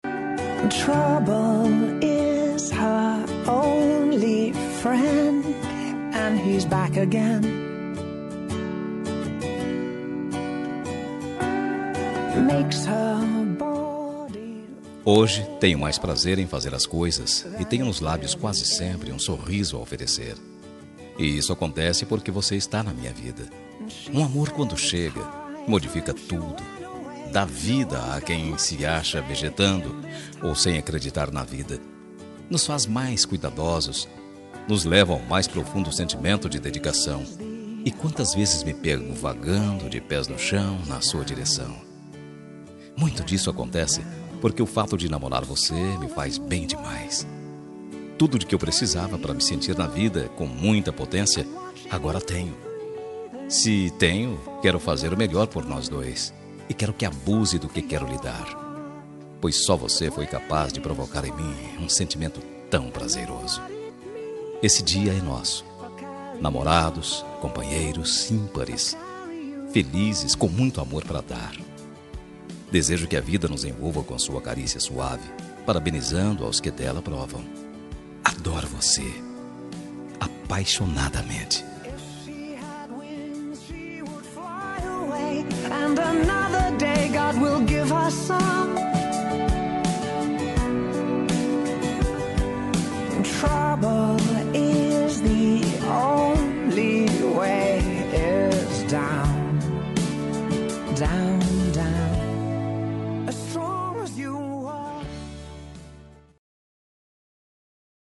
Dia dos Namorados – Para Namorada – Voz Masculina – Cód: 6880